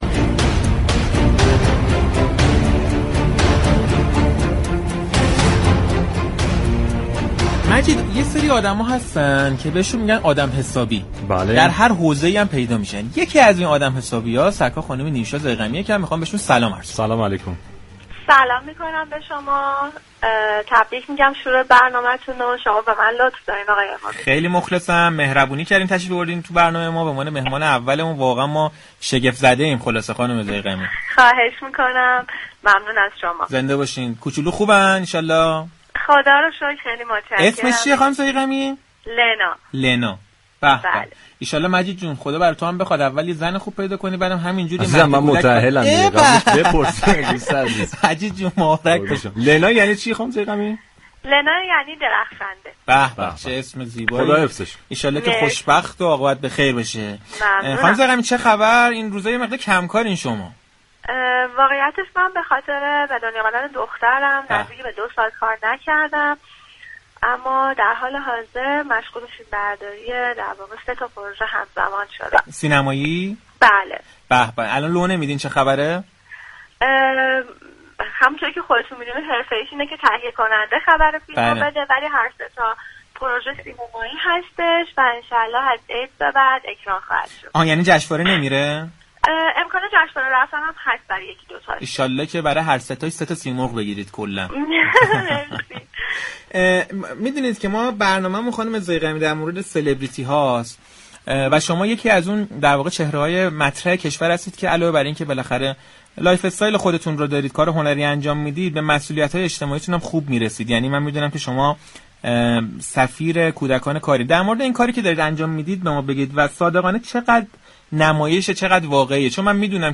نیوشا ضیغمی، بازیگر سینما شب گذشته در اولین قسمت از برنامه پشت صحنه از رادیو تهران گفت: به خاطر به دنیا آمدن فرزندم، نزدیك به دو سال است كه كار نكرده‌ام اما در حال حاضر مشغول تصویربرداری سه پروژه سینمایی به صورت همزمان هستم.